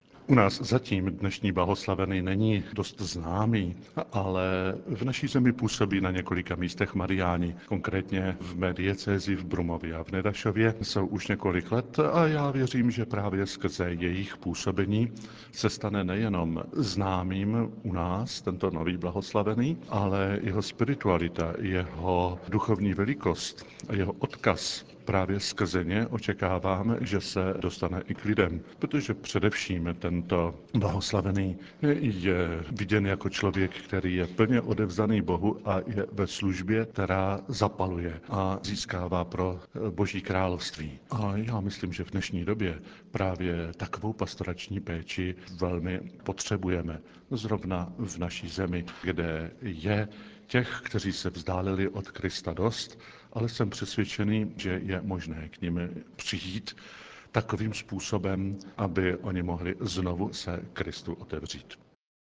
Z dějiště beatifikace v polské Licheni hovořil pro Vatikánský rozhlas olomoucký arcibiskup Mons. Jan Graubner: